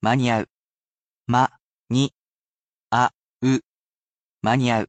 Though he’s a robot, he’s quite skilled at speaking human language. He’s lovely with tones, as well, and he will read each mora so you can spell it properly in kana.